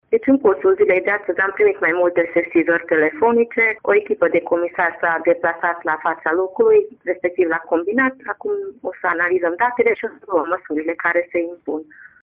Comisarul șef al Gărzii de Mediu Mureș, Szekely Annamaria: